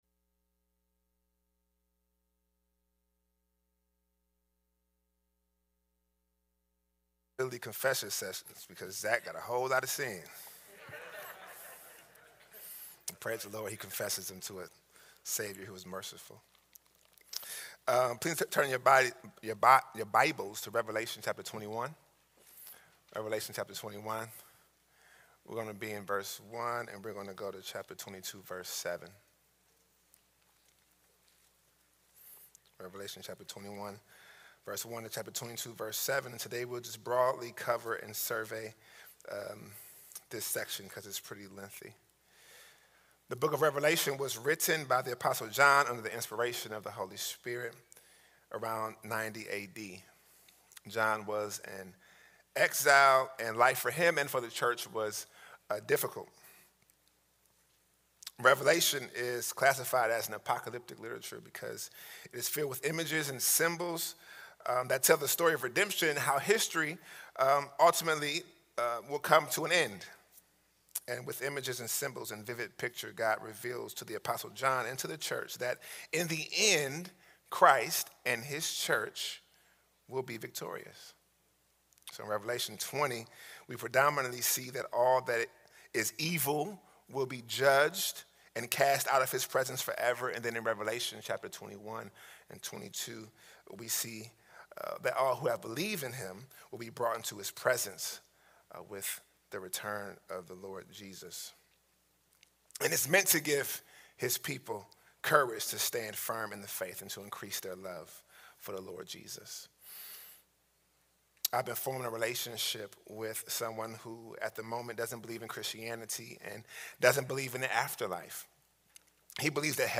FBC Sermons